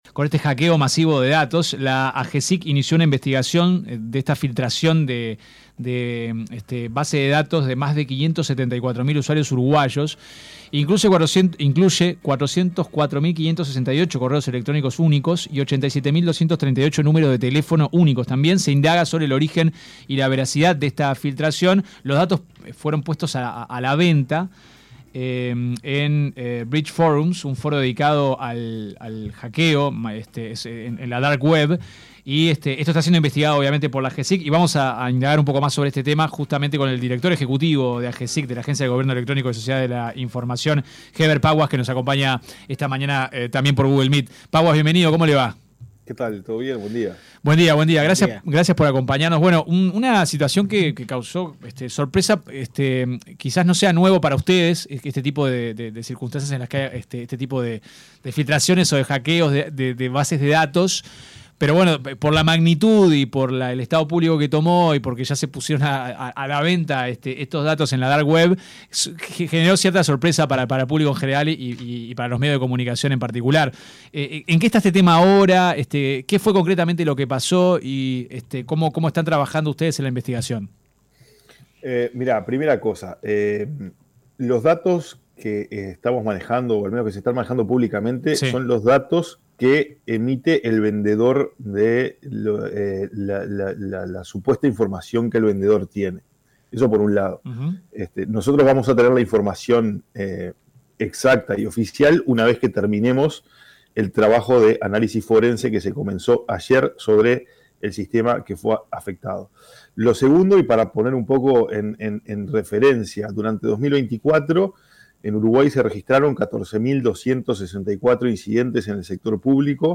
Escuche la entrevista completa Hebert Paguas dijo se inició una investigación sobre filtración de una supuesta base de datos de más de 574.000 usuarios uruguayos donde se incluyen correos electrónicos y celulares.
Paguas en entrevista con 970 Noticias dijo que la modalidad más sencilla de hackeo es el “phishing”.